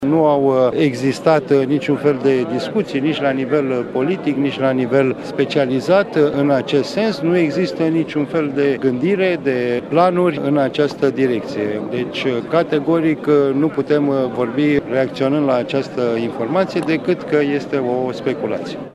Ministrul Apărării, Mihnea Motoc, a calificat articolul respectiv drept pură speculaţie: